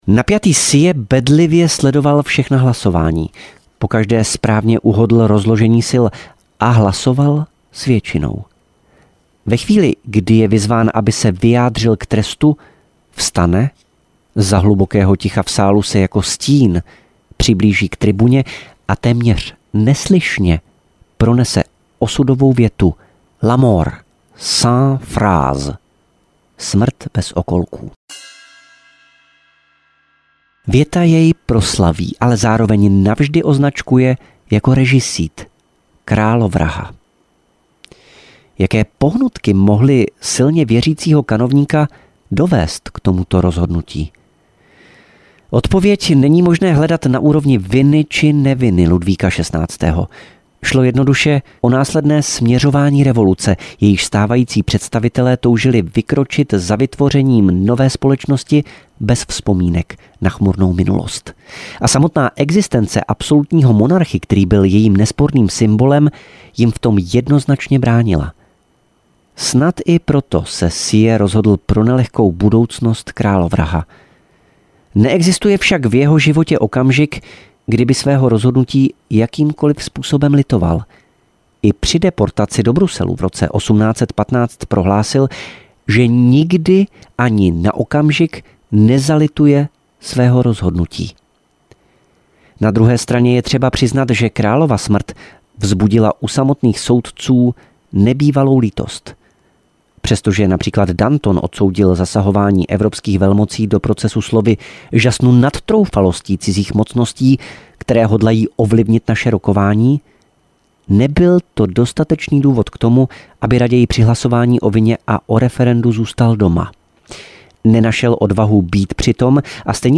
Ukázka z knihy
francouzska-revoluce-sieyes-ten-ktery-vsechny-prezil-audiokniha